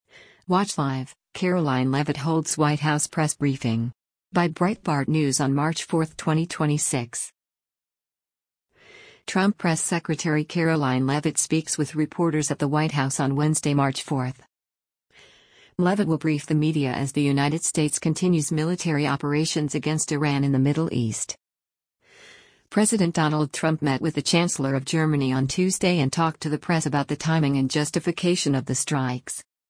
Trump Press Secretary Karoline Leavitt speaks with reporters at the White House on Wednesday, March 4.